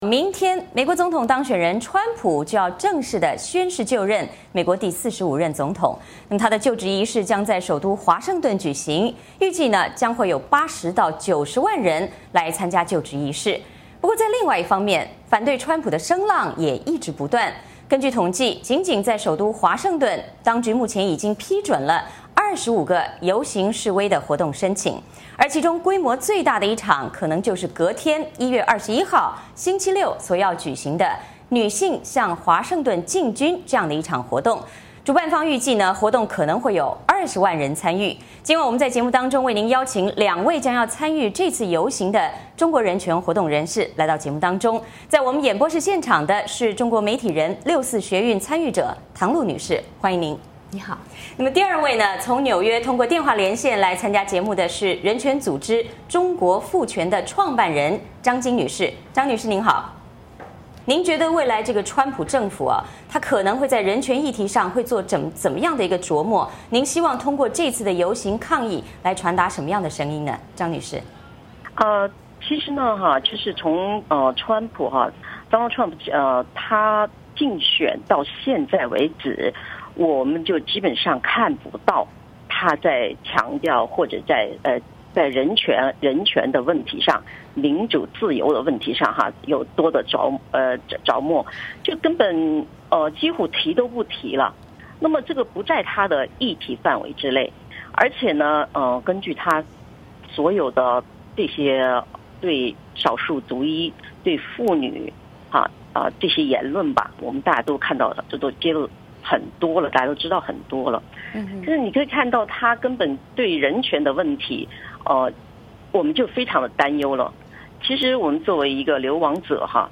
其中规模最大的要数1月21日星期六举行的“女性向华盛顿进军”(Women's March on Washington)，主办方预计这场示威活动将有20万人参加。今天来到节目现场以及通过Skype参加节目的就有两名将要参加这次大游行的在美华人人权活动人士。